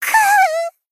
BA_V_Kotori_Battle_Damage_3.ogg